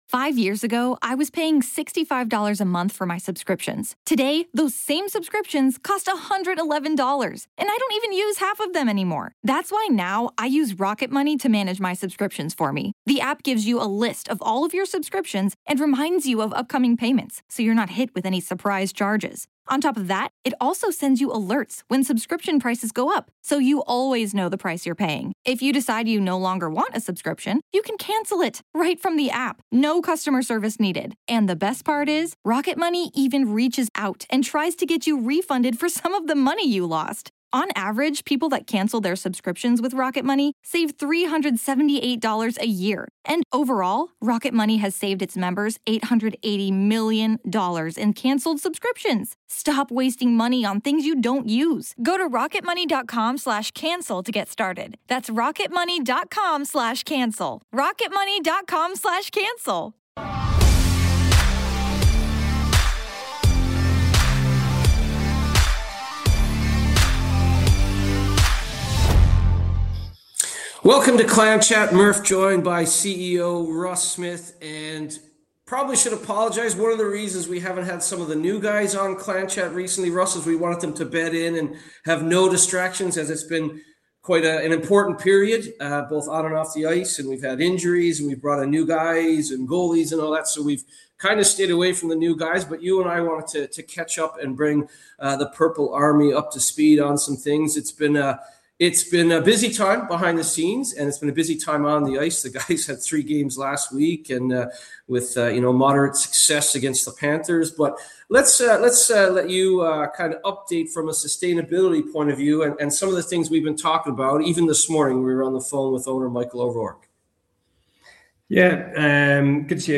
It’s an insightful conversation you won’t want to miss — hit play and get the latest from inside the Clan.